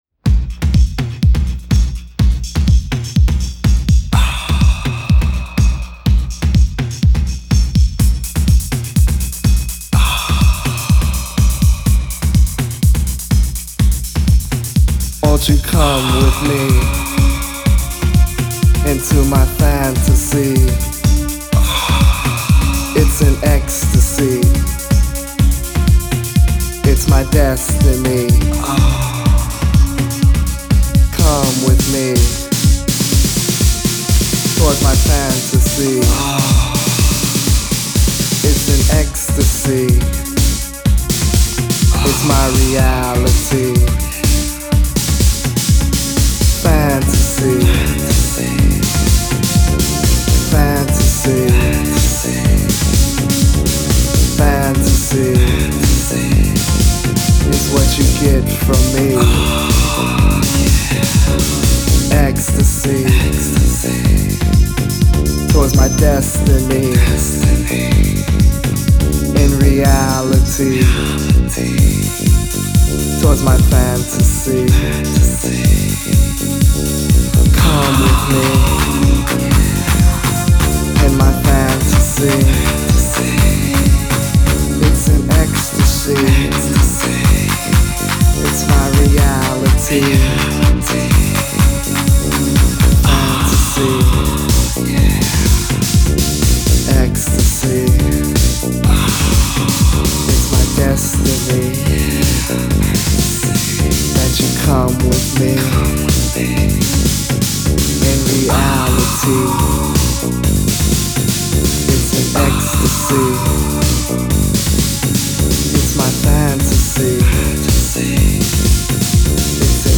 Genre: Electronic, Acid House, Deep House